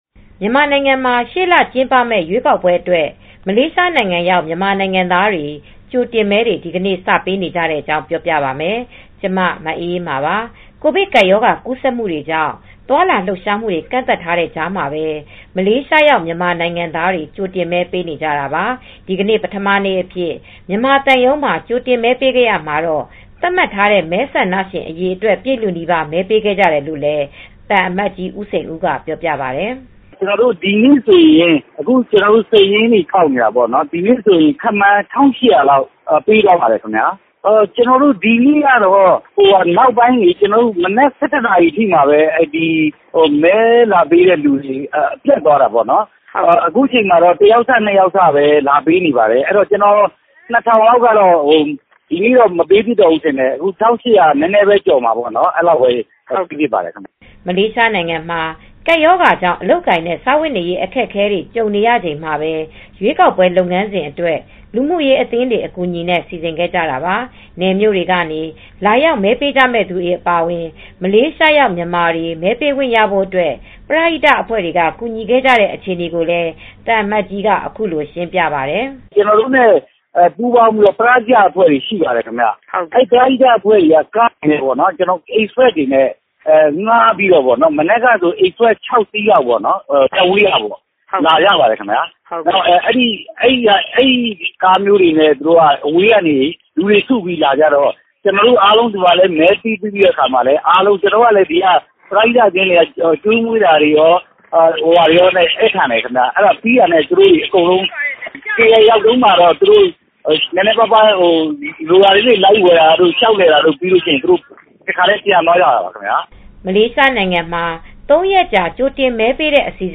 ကိုဗစ်ကပ်ရောဂါ ကူးစက်မှုတွေကြောင့် သွားလာလှုပ်ရှားမှုတွေ ကန့်သတ်ထားတဲ့ကြားမှာပဲ မလေးရှားရောက် မြန်မာနိုင်ငံသားတွေ ကြိုတင်မဲ ပေးနေကြတာပါ။ ဒီကနေ့ ပထမနေ့အဖြစ် မြန်မာသံရုံးမှာ ကြိုတင်မဲပေးရာမှာတော့ သတ်မှတ်ထားတဲ့ မဲဆန္ဒရှင် အရေအတွက် ပြည့်လုနီးပါး မဲပေးခဲ့ကြတယ်လို့လည်း သံအမတ်ကြီး ဦးစိန်ဦးက ပြောပါတယ်။